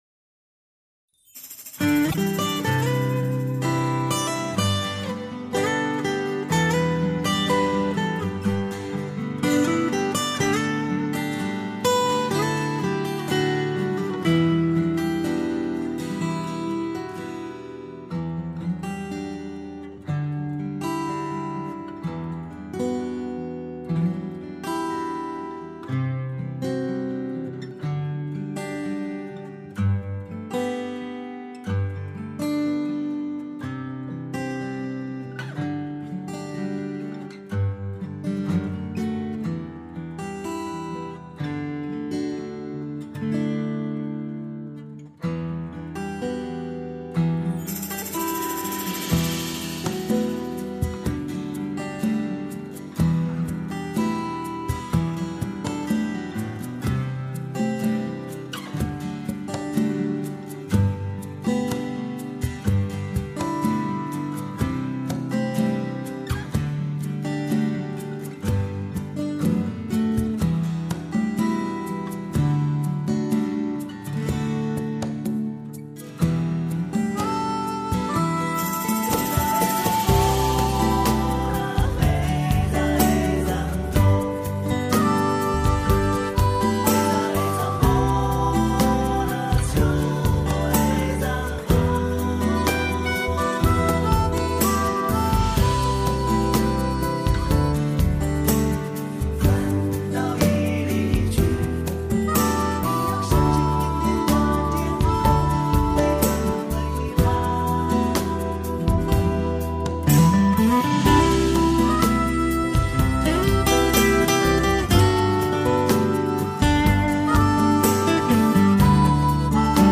调式 : G 曲类